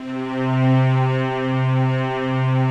Index of /90_sSampleCDs/Optical Media International - Sonic Images Library/SI1_Swell String/SI1_Octaves